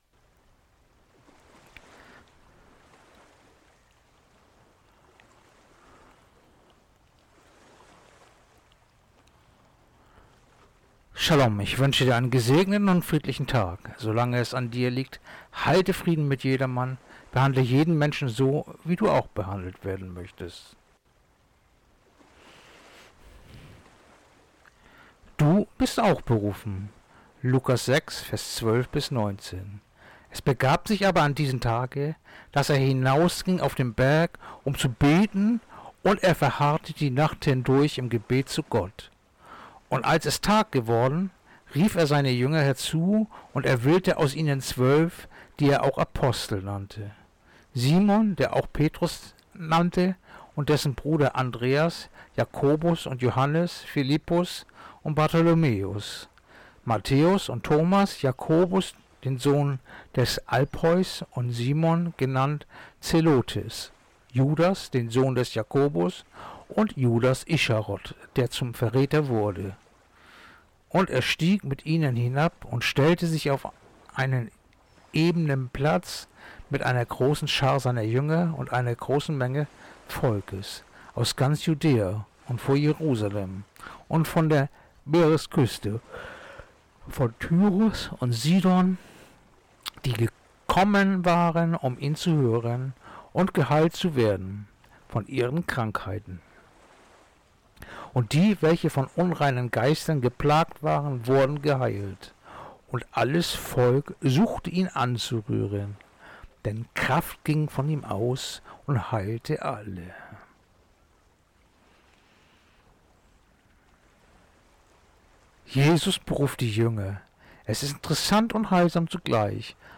Andacht-vom-28.-Januar-Lukas-6-12-19